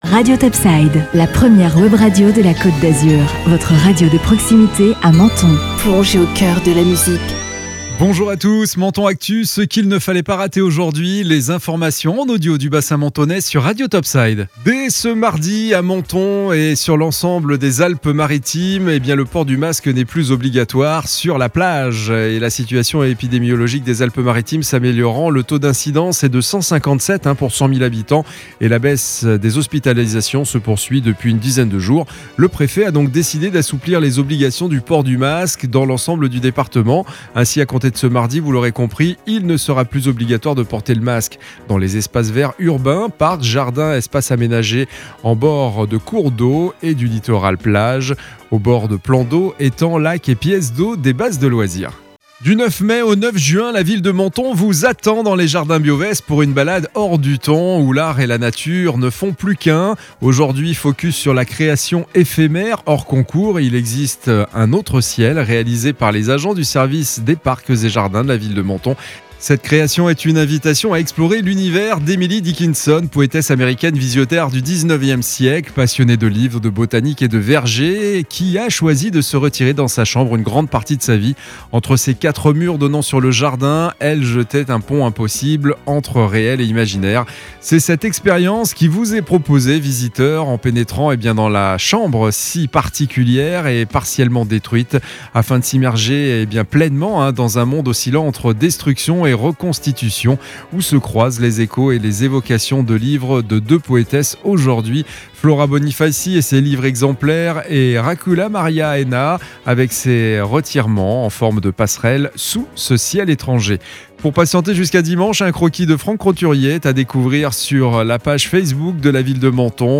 Menton Actu - Le flash info du mardi 04 mai 2021